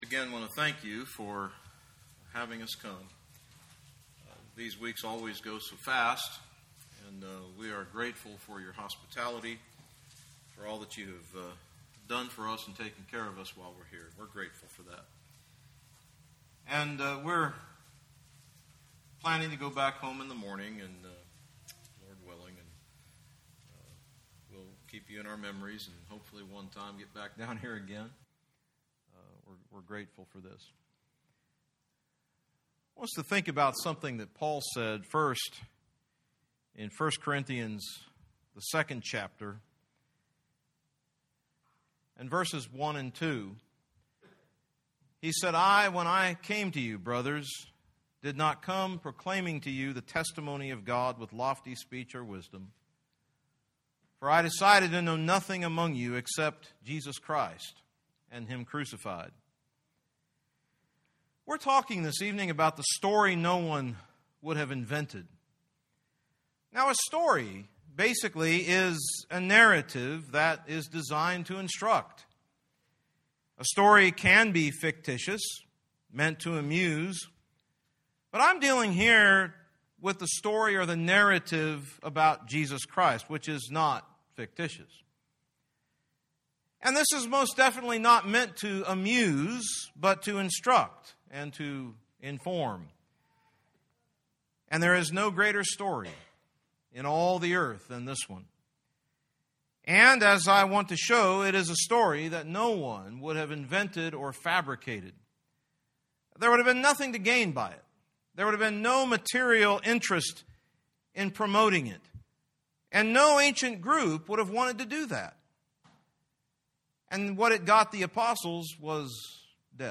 Gospel Meetings Service Type: Gospel Meeting Preacher